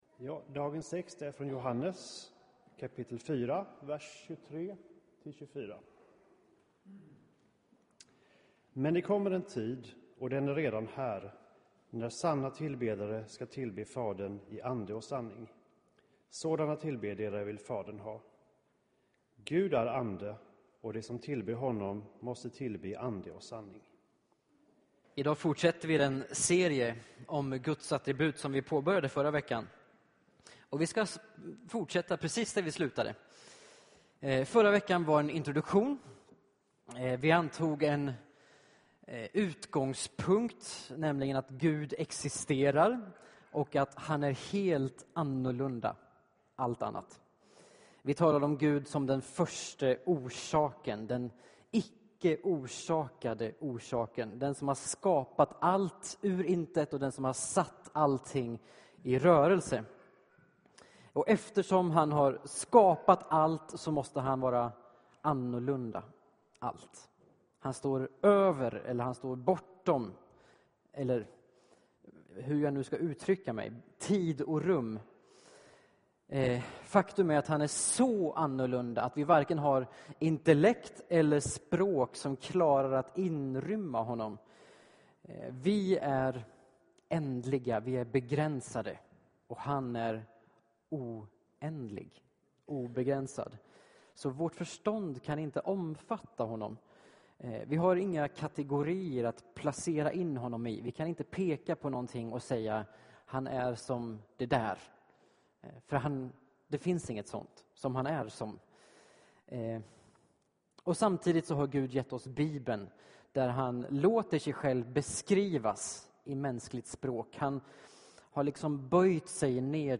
Inspelad i Baptistkyrkan Tabernaklet i Göteborg 2015-01-18.